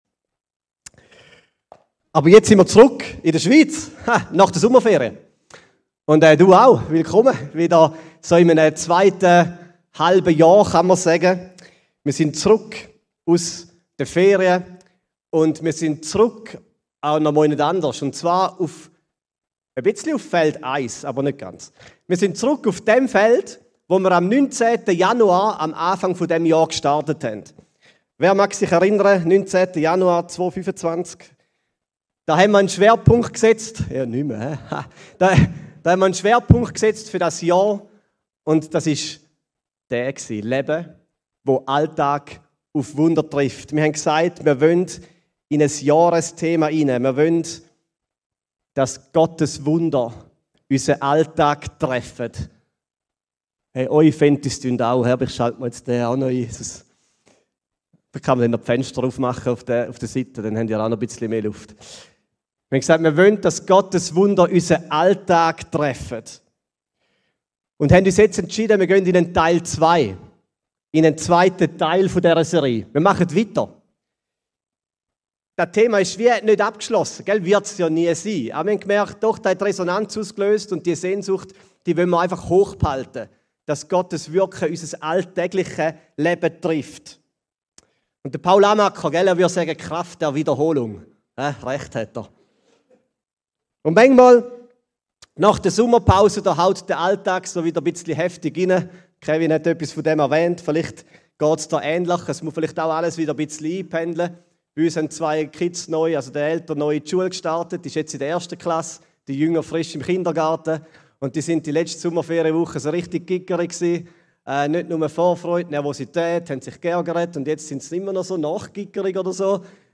Diese Predigt zeigt, wie du seine Stimme hören, mutig deine Geschichte mit Jesus erzählen, für Menschen beten und sie zu ihm einladen kannst. Es geht nicht um grosse Events, sondern um kleine, natürliche Begegnungen, in denen Gottes Kraft sichtbar wird.